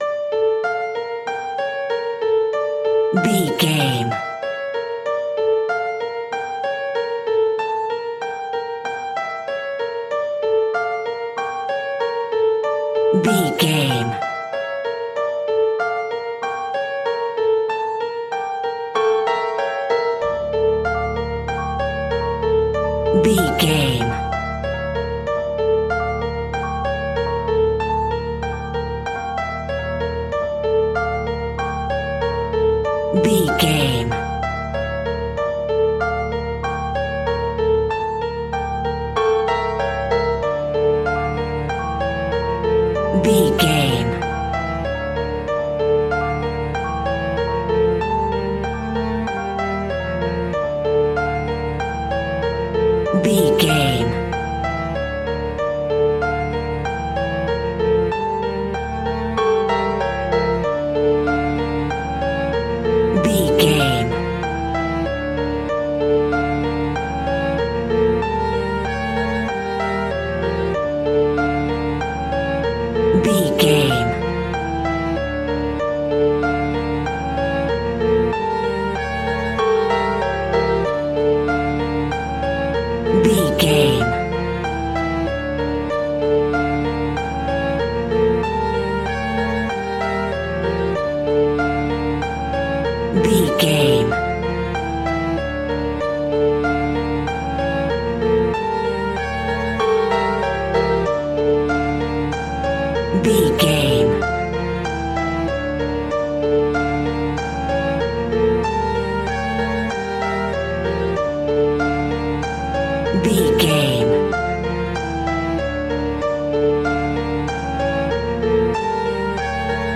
In-crescendo
Aeolian/Minor
tension
ominous
dark
haunting
eerie
piano
strings
synth
pads